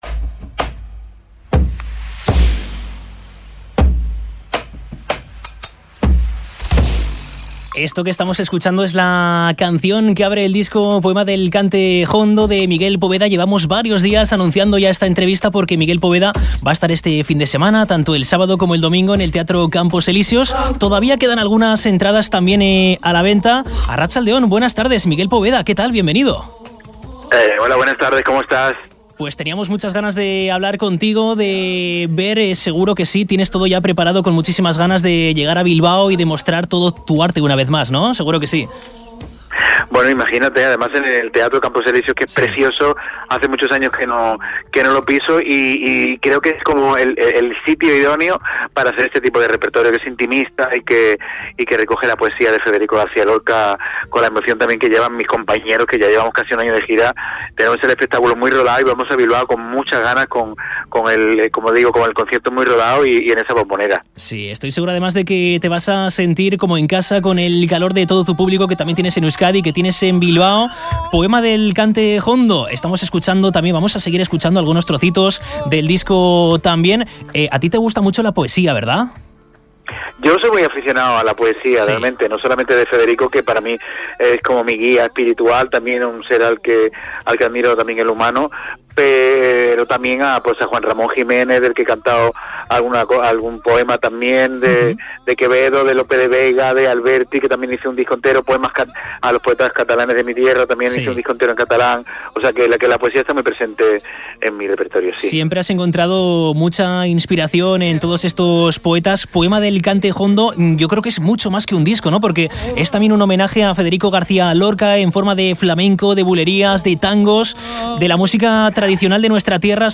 Entrevista a Miguel Poveda (20/03/2025)